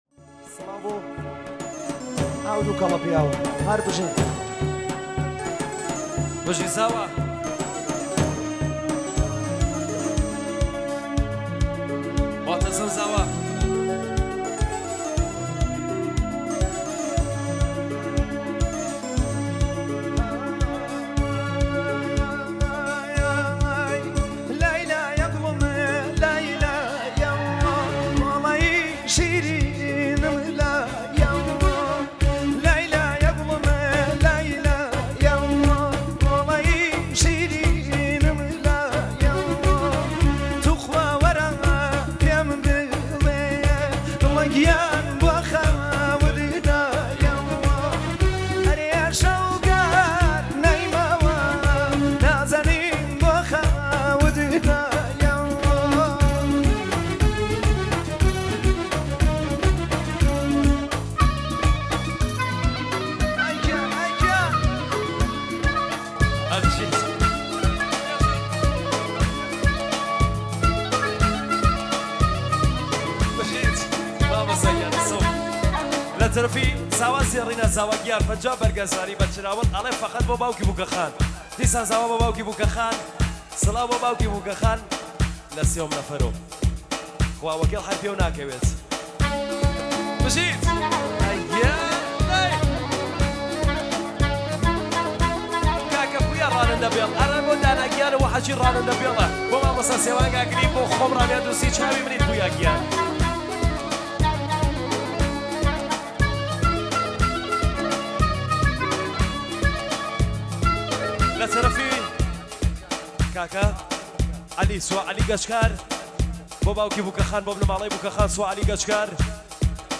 کیبورد
کردی شاد مخصوص جشن